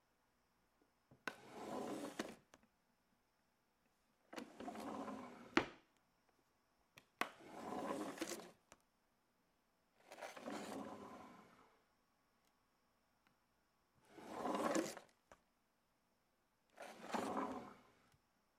抽屉打开和关闭
描述：打开和关闭木制抽屉的多个镜头